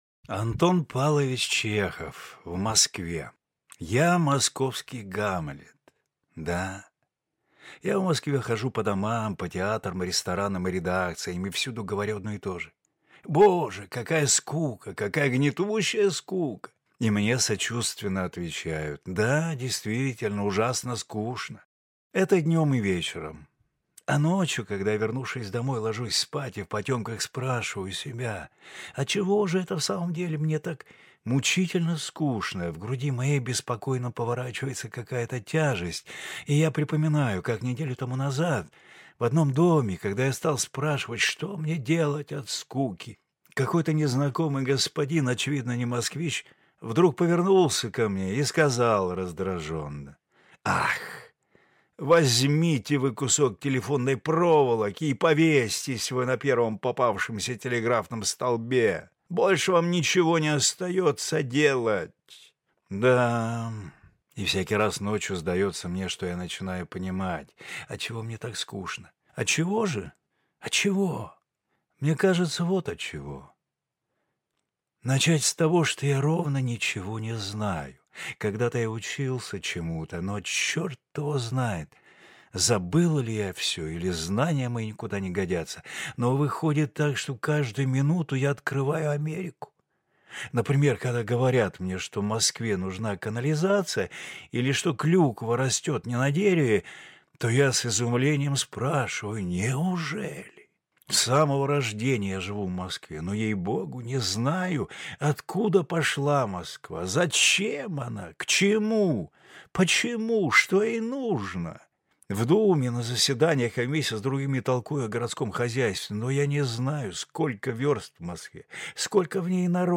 Аудиокнига В Москве | Библиотека аудиокниг